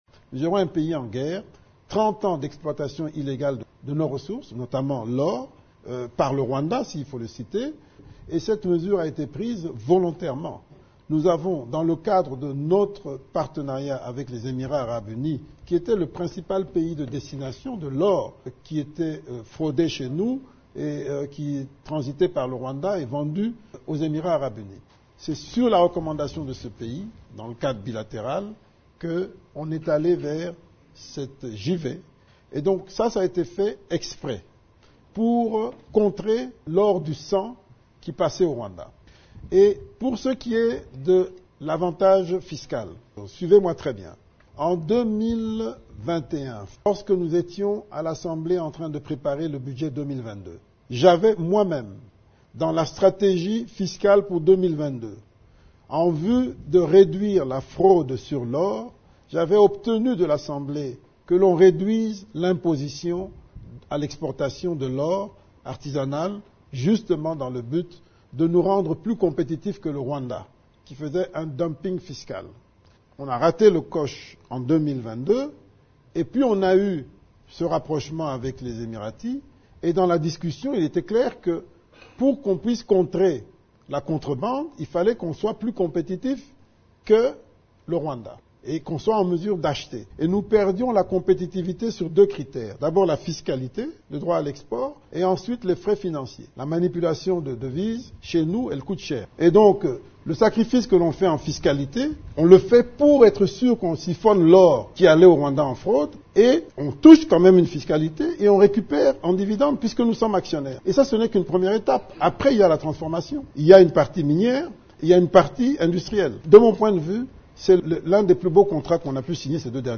Le ministre des Finances s’exprimait au cours de le rencontre consacrée à la visite du président Tshisekedi en Chine.